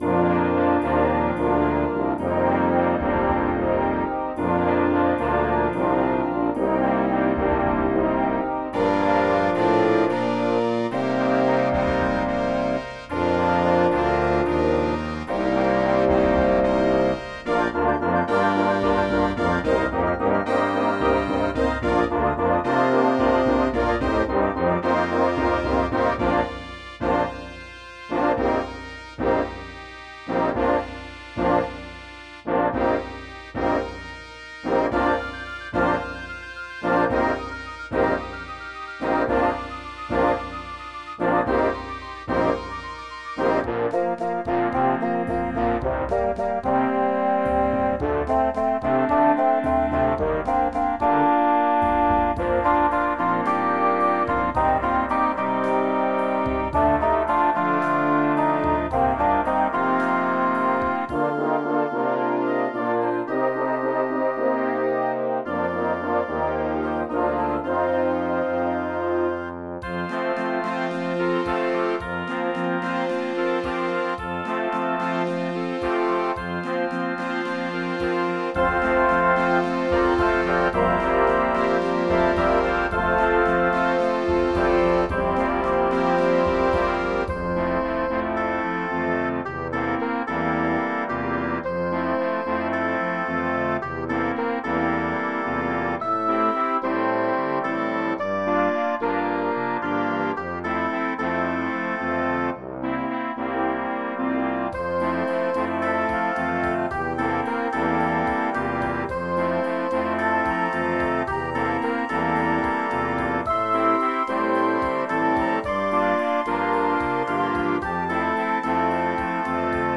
Sambacerto - orquestra de sopros